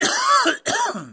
Husten klingelton kostenlos
Kategorien: Soundeffekte
Husten.mp3